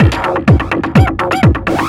DS 126-BPM B3.wav